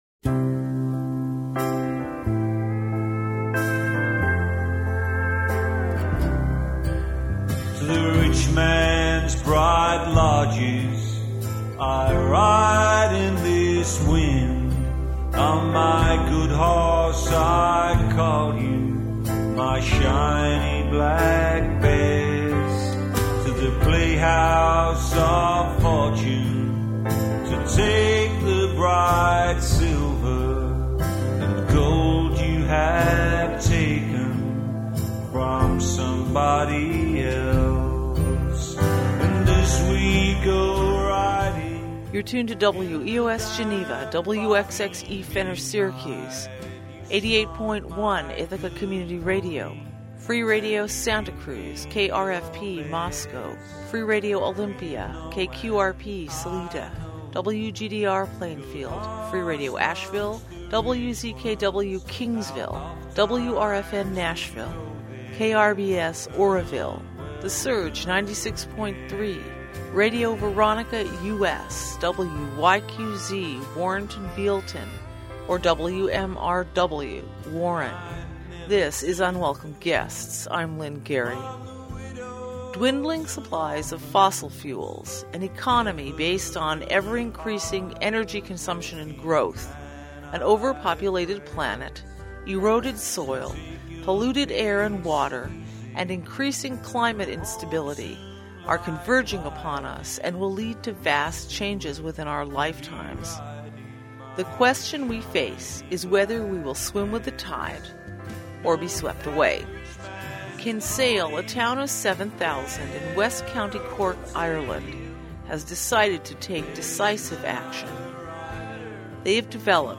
Download Hour1 Download Hour2 This week we play two presentations from the "Fuelling the Future" conference in Kinsale, Ireland, on preparing for energy descent. We conclude with a new study confirming benefits of organic farming.